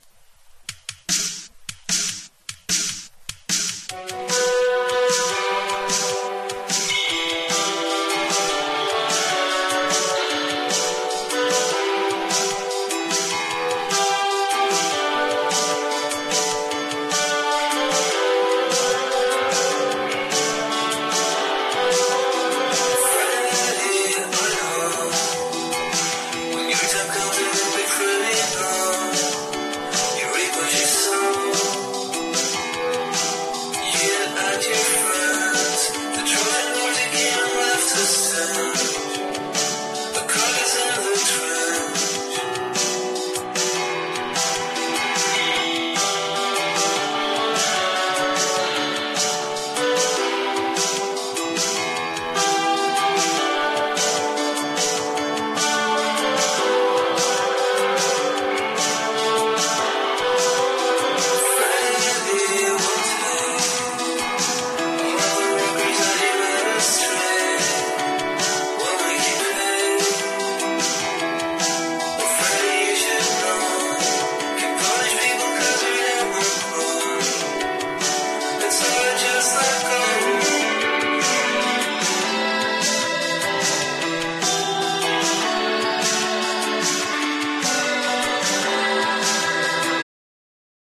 NEO ACOUSTIC / GUITAR POP (90-20’s)
• 盤面 : EX+ (美品) キズやダメージが無く音質も良好